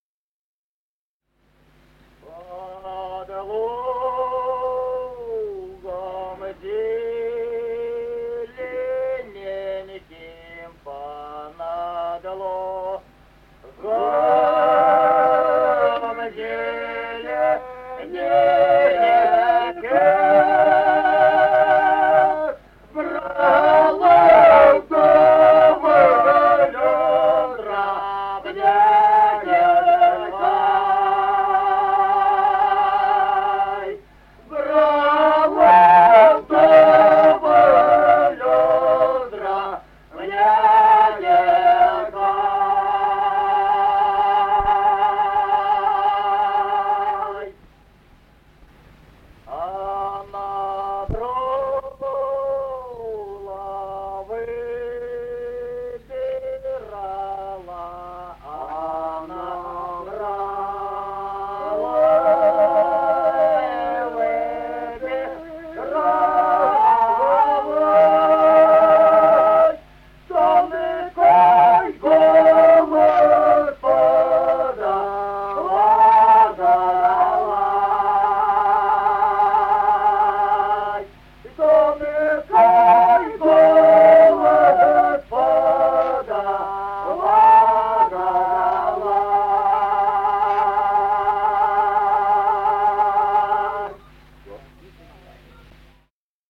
Музыкальный фольклор села Мишковка «По-над лугом зелененьким», лирическая.